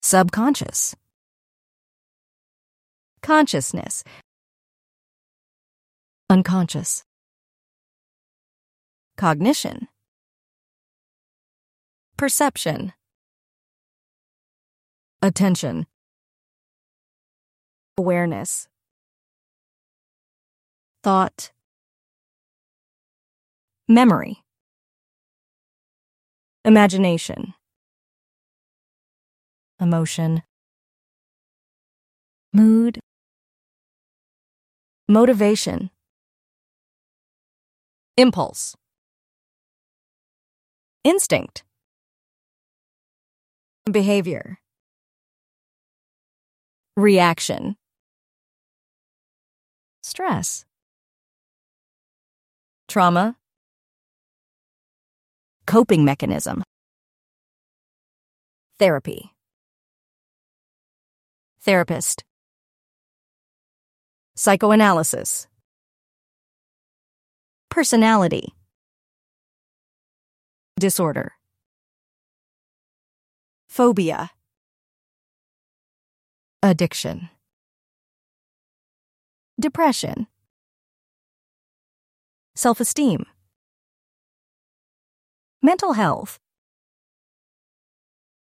Pliki audio MP3 z nagraniami słów i dialogów do pobrania oraz na platformie